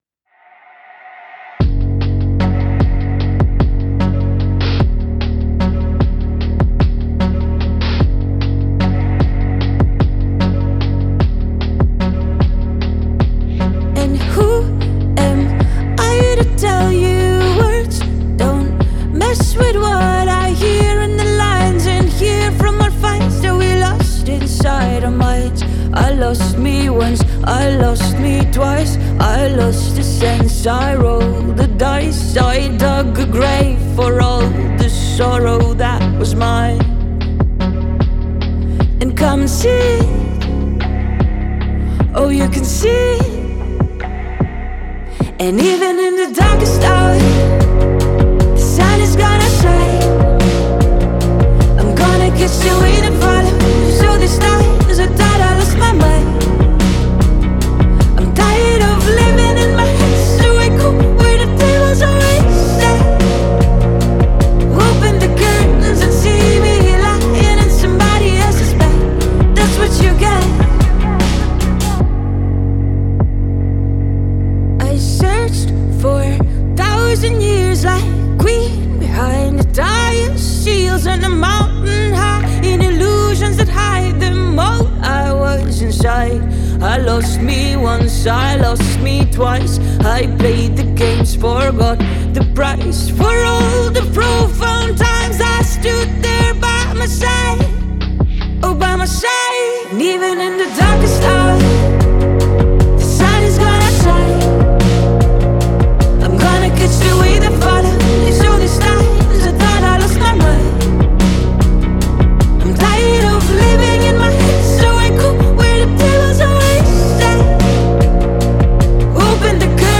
A powerful anthem of resilience and self-discovery!